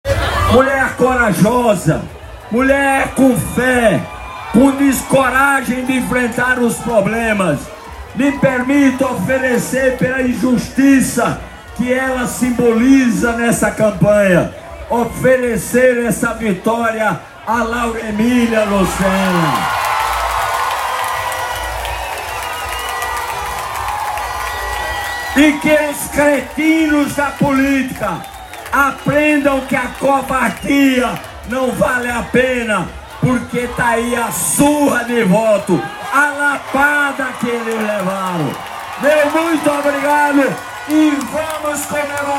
[ÁUDIO] Em tom de emoção e mágoa Cícero Lucena dedica vitória à esposa e xinga adversários - Diário de Vanguarda